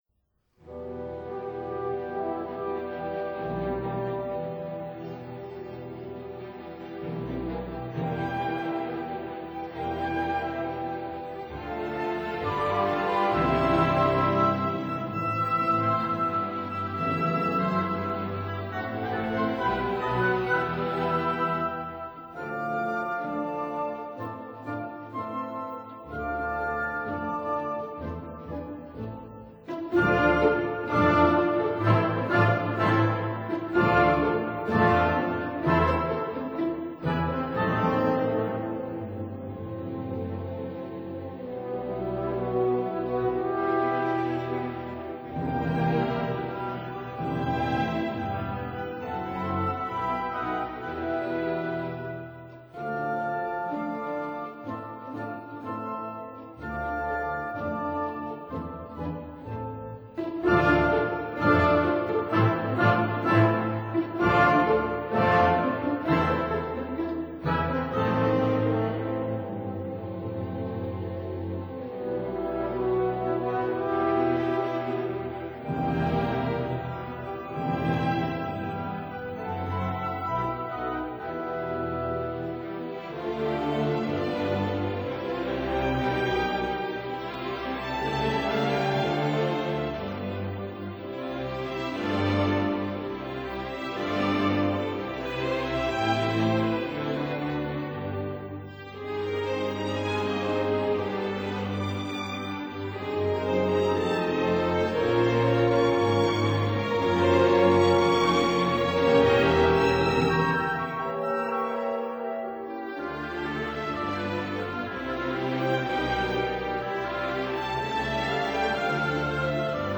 Symphony No. 2 in C minor
•(05) Symphony No. 3 in G minor, Op. 227